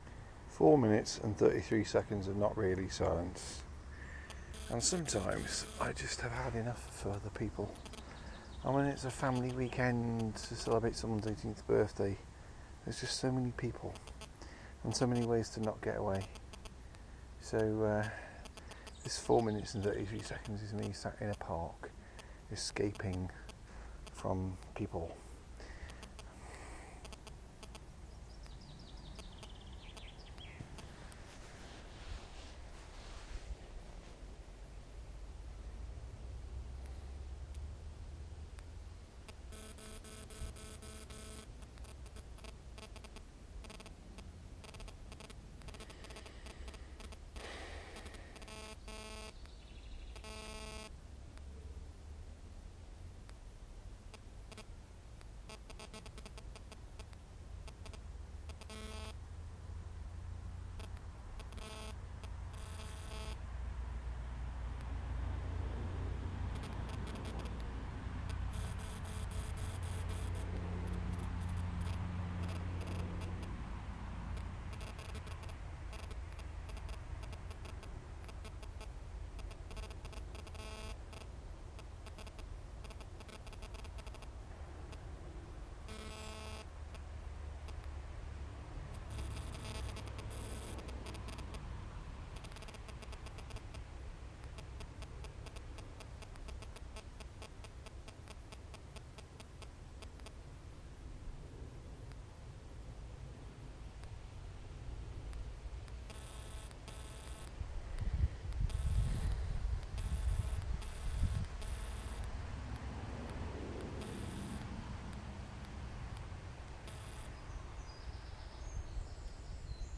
4m33s of escaping family in Sherwood Forest